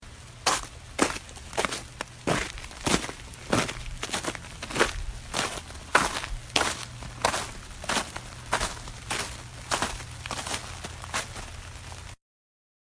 冬天" 脚步声 靴子正常包装的雪和一些有点脆的中等速度的目的和停止启动
描述：脚步声正常包装雪和一些有点脆脆的中速和目的，停止starts.flac
Tag: 正常 靴子 包装 脚步声